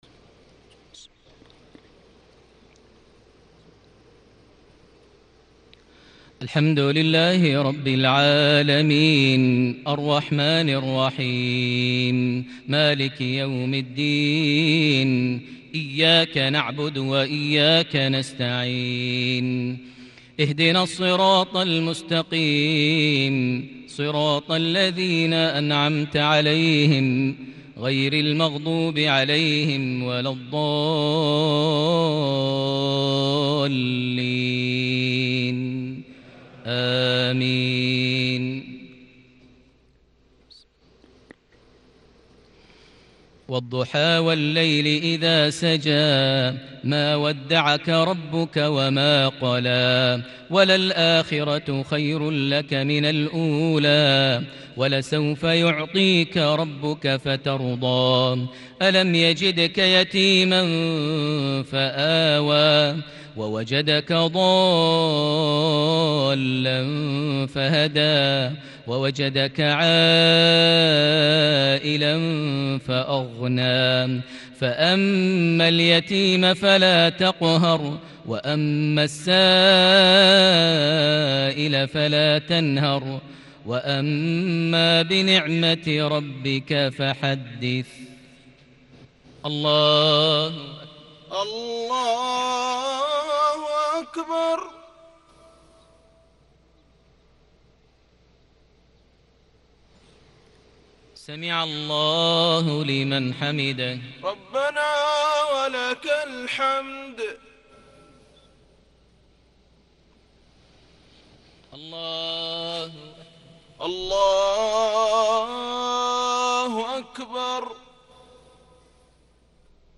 صلاة الجمعة ٤ رجب ١٤٤١هـ سورتي الضحى و العصر > 1441 هـ > الفروض - تلاوات ماهر المعيقلي